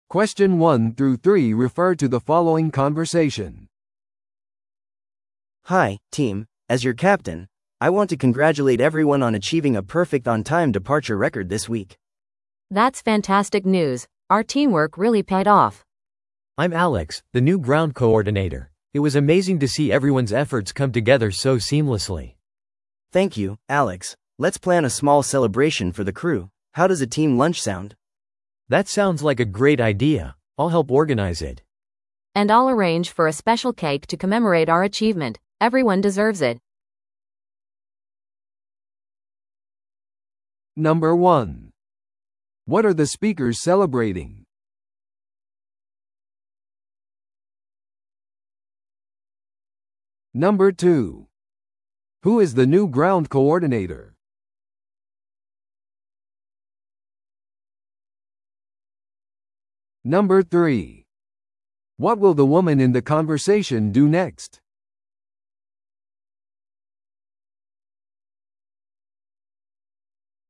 No.3. What will the woman in the conversation do next?